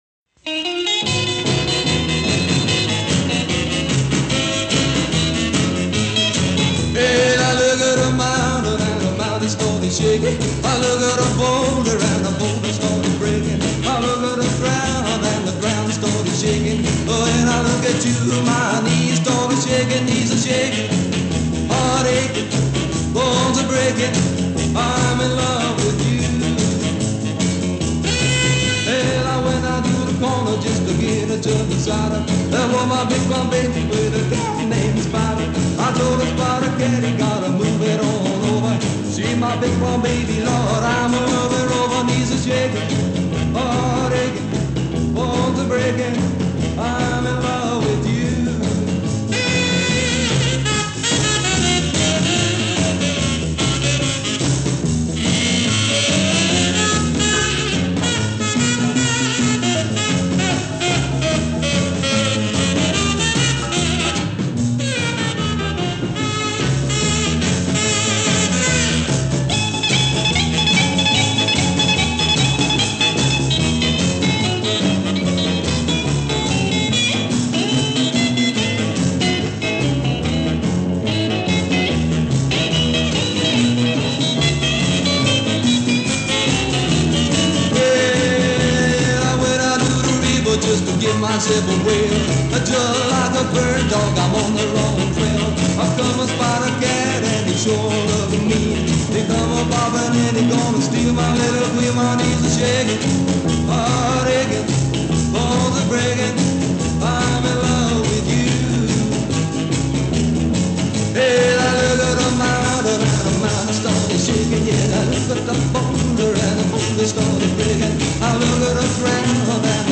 разудалое рокабилли